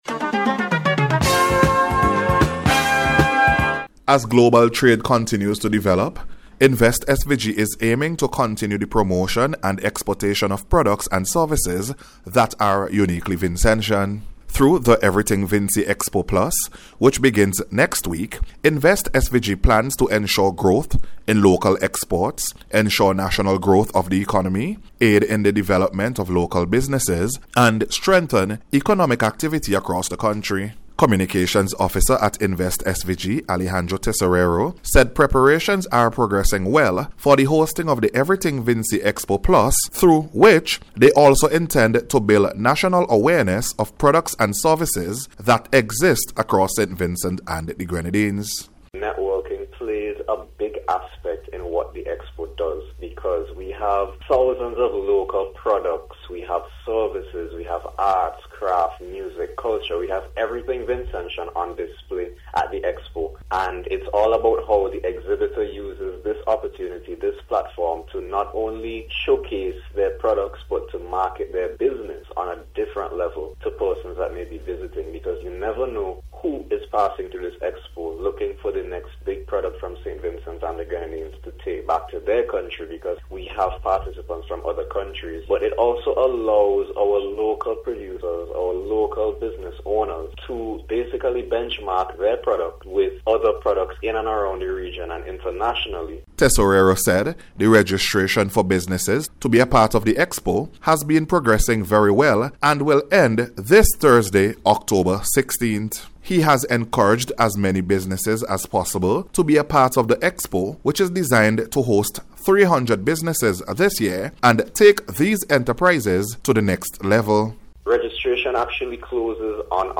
NBC’s Special Report- Monday 13th October,2025